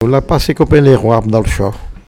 Enquête Arexcpo en Vendée
locutions vernaculaires
Catégorie Locution